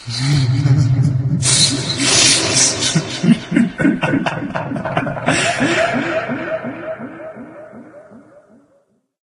controller_attack_0.ogg